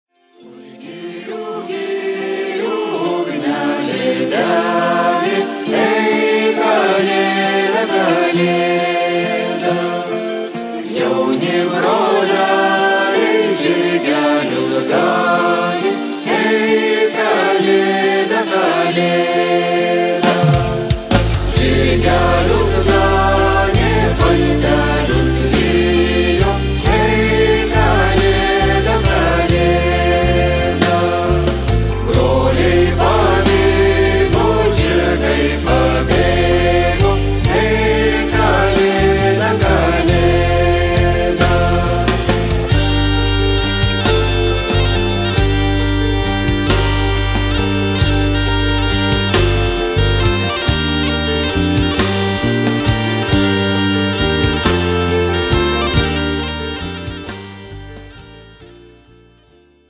bansuri, viola, vocals, jew's harp, bagpipe
acoustic guitar, electric guitar
drums
tabla